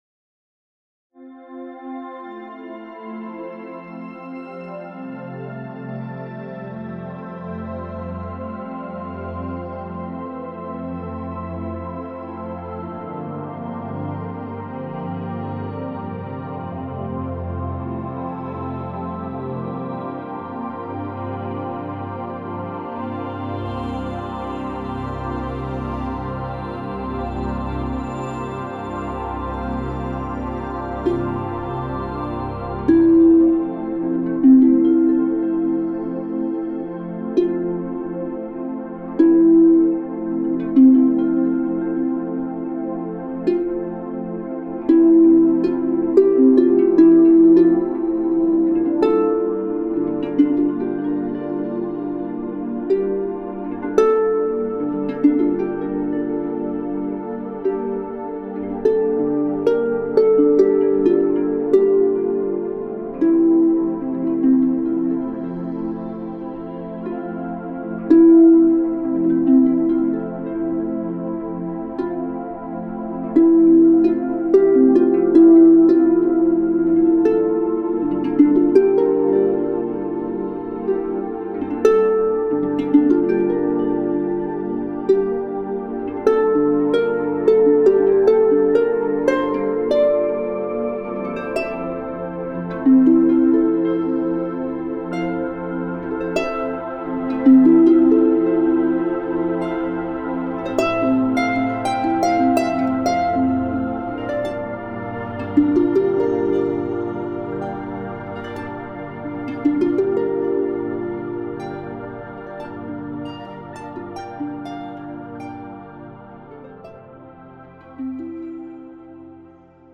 angelic harp music
crystalline sounds
Irish harp
Instruments featured: Irish harp. 59 minutes